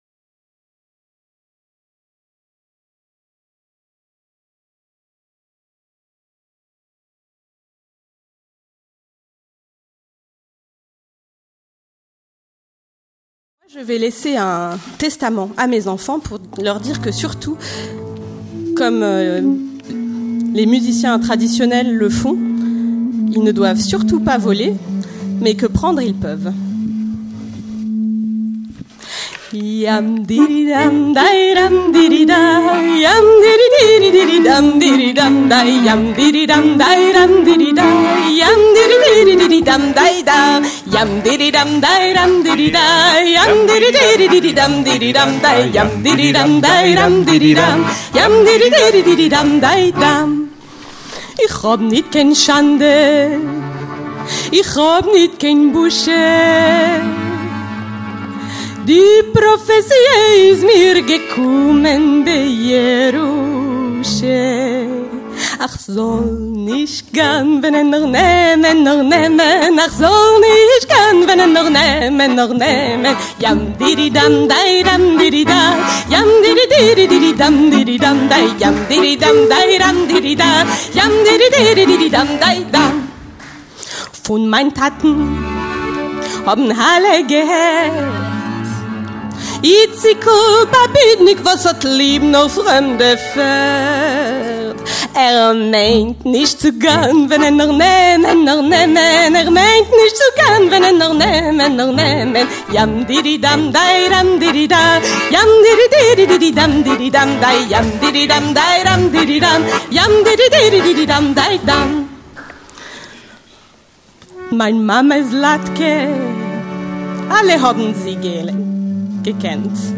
Schauen Sie VideoAusschnitte aus der erste Ausgabe des Galakonzerts des Europäischen Instituts für Jüdische Musik, “Jüdische Musik in all ihren Zuständen”, das am Sonntag, den 8. November 2015, um 17.30 Uhr, im Adyar Theater vor einem begeisterten Publikum von fast 350 Zuschauern, statt.
Repertoire unbekannter jiddischer Lieder, die von großen Sammlern wie Ruth Rubin (1906-2000), Moishe Beregovski (1892-1961) und Zusman Kisselgof (1878-1939) vor dem Vergessen bewahrt wurden.
Gesang und Violine
Kontrabass
Klarinette
Akkordeon